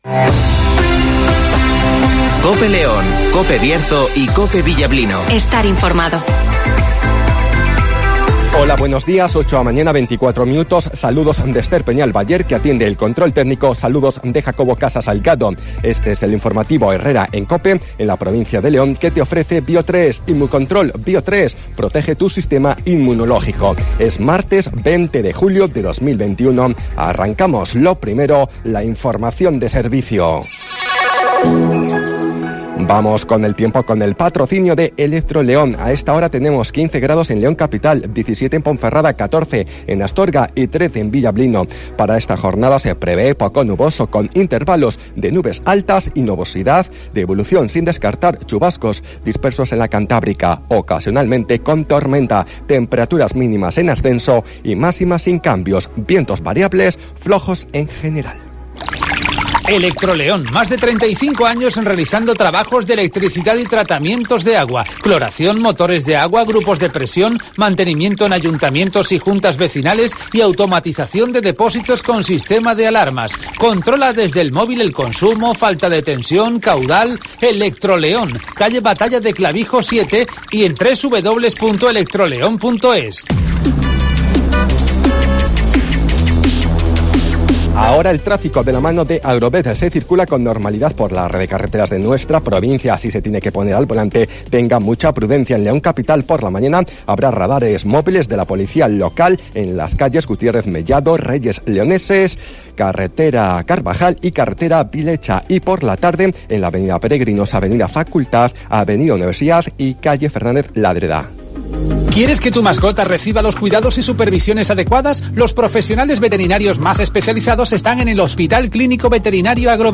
INFORMATIVOS
Repaso a la actualidad informativa de la provincia de León. Escucha aquí las noticias de la comarca con las voces de los protagonistas.